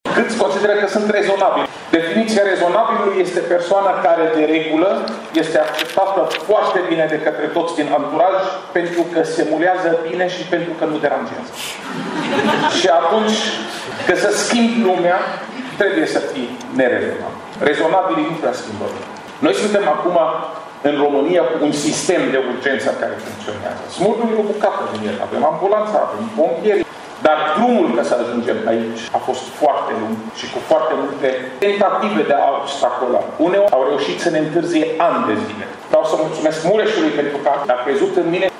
A doua ediție a conferințelor TEDx are loc pe parcursul zilei de astăzi la sala Studio a Universității de Arte și Teatru din Tîrgu-Mureș.